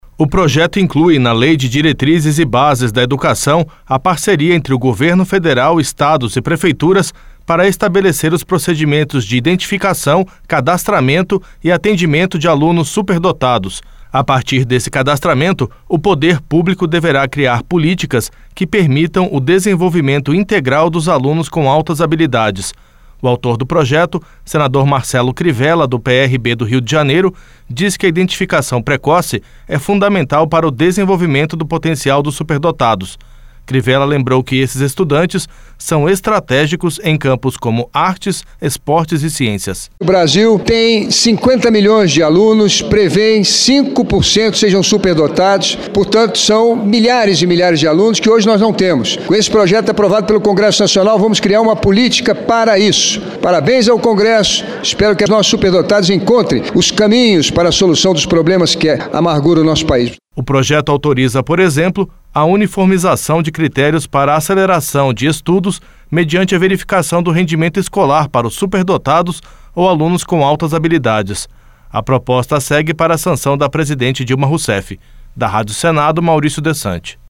O autor do projeto, senador Marcelo Crivella, do PRB do Rio de Janeiro, diz que a identificação precoce é fundamental para o desenvolvimento do potencial dos superdotados.